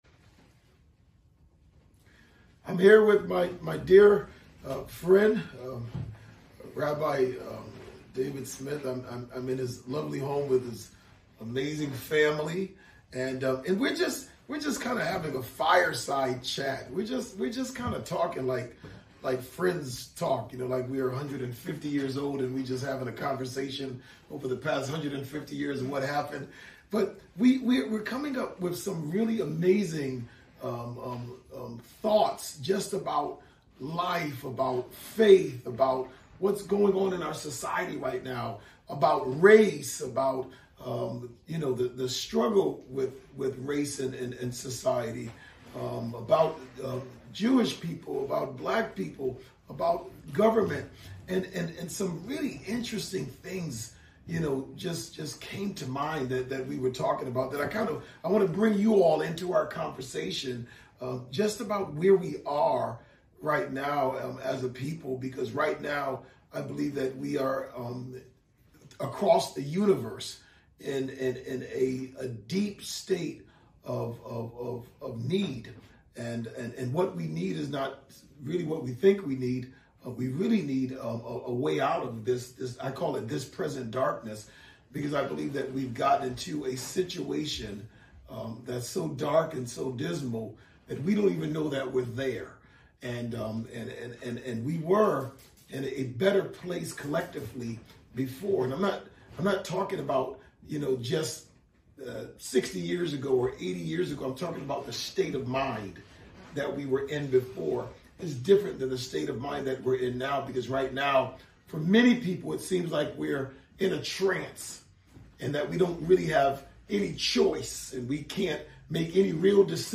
Two of our planet's wisest clerics talk about what's going on in the world right now and why people of faith seem to be trapped—amazing discussion from two loving souls.